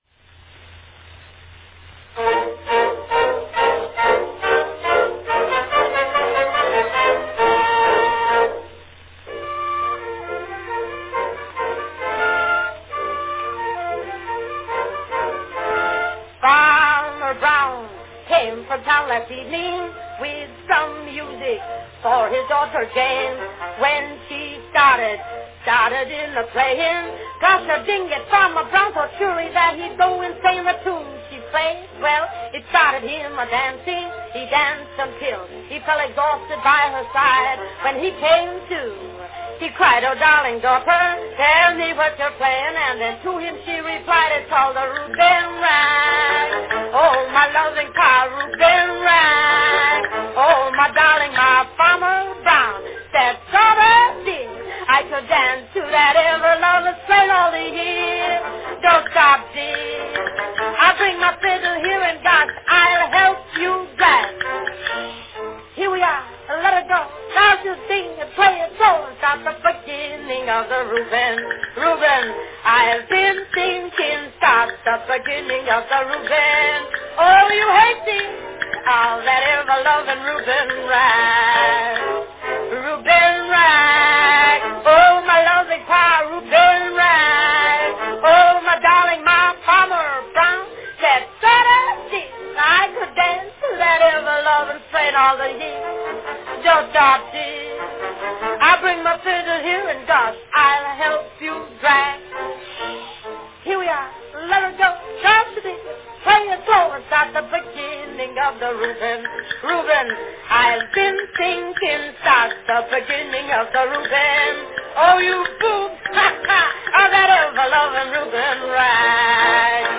Category Comic song
Announcement None